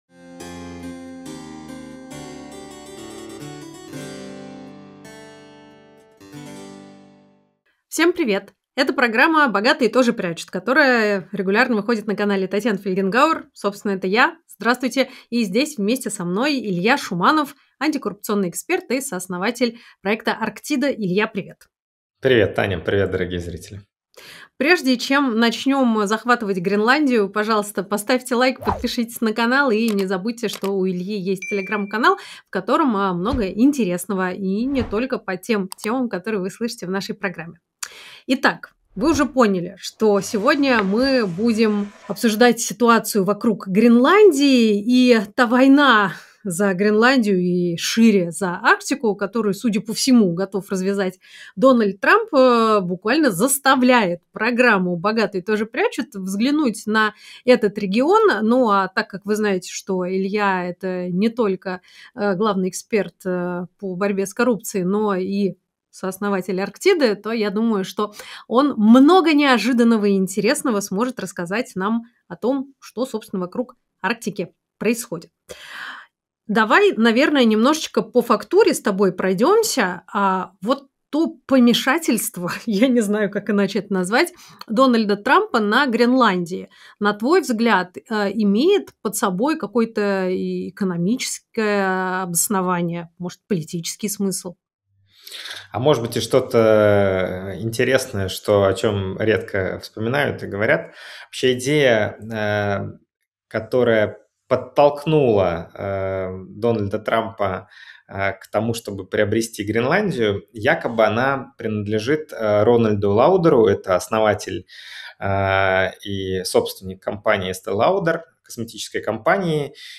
Эфир